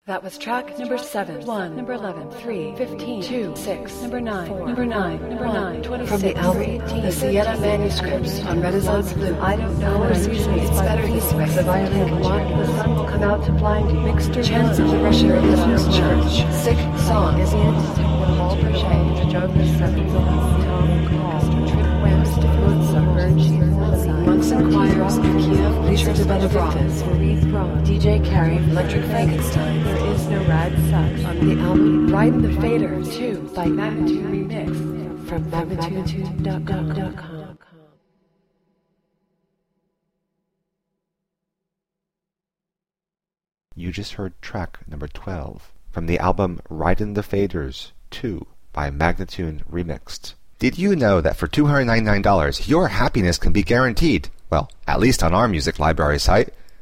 Electronica, Rock, Alt Rock, Remix